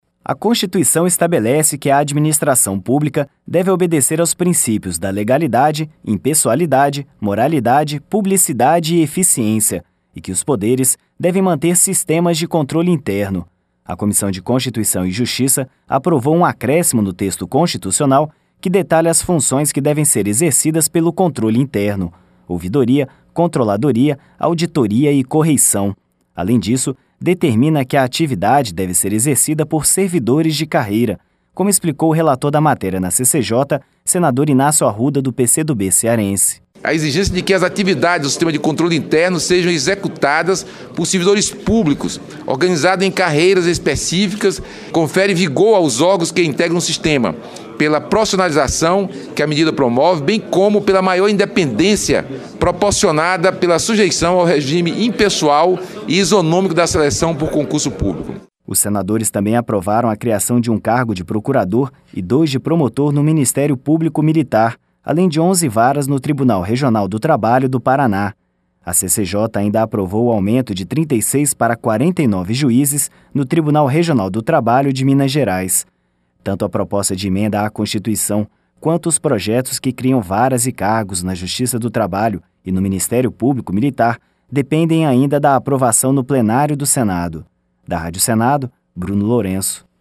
Além disso, determina que a atividade deve ser exercida por servidores de carreira, como explicou o relator da matéria na CCJ, senador Inácio Arruda, do PC do B cearense.